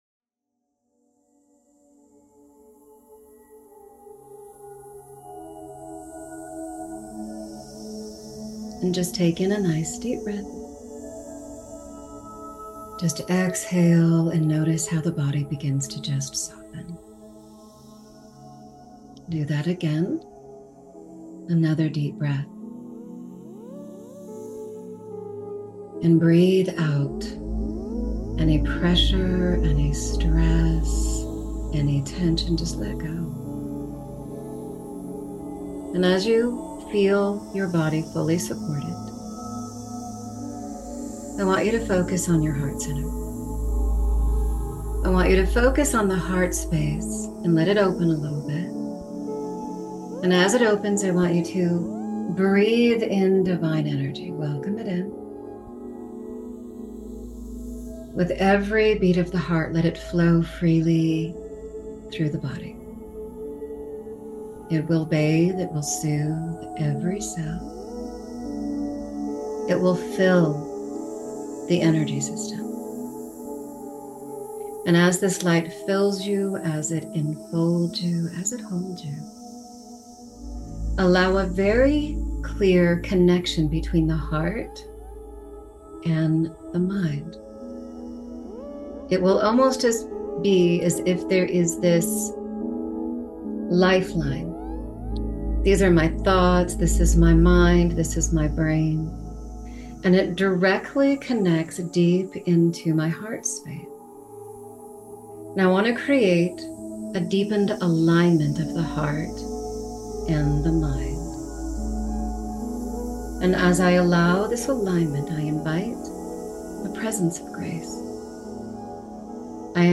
Guided Meditation with hypnosis to open your spirit to be filled with and surrounded by grace.